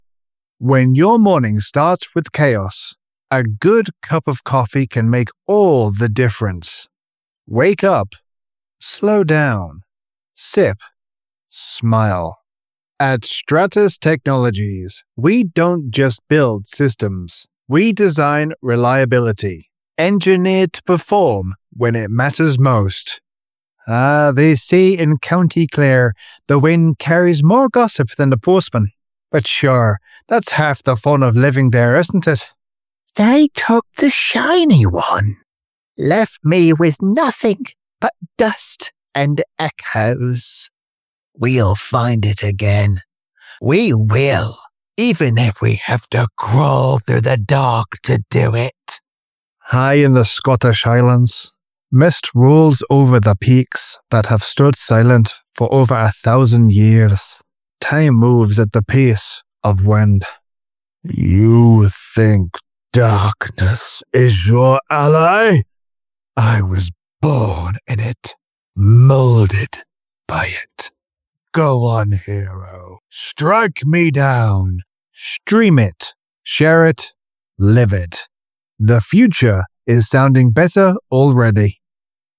Browse professional voiceover demos.
My voice has been described as warm, friendly, sincere and calming.
1104Dry_Studio_Demo.mp3